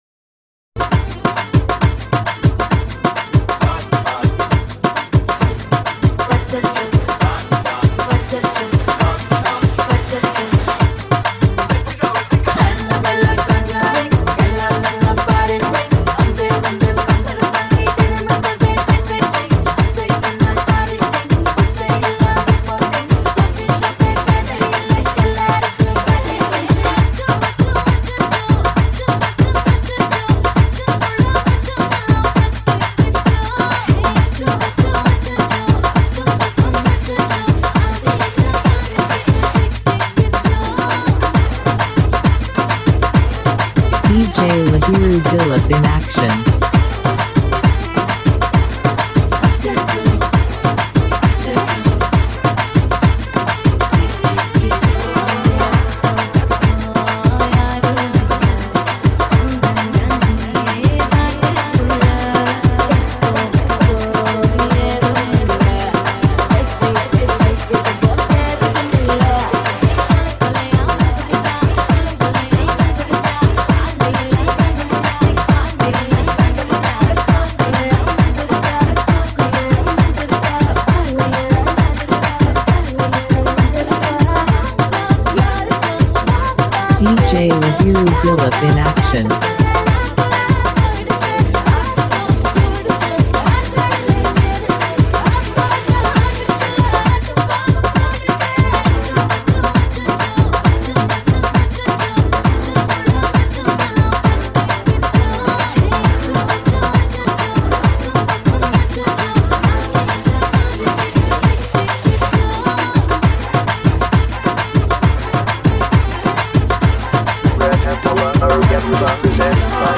High quality Sri Lankan remix MP3 (3).
high quality remix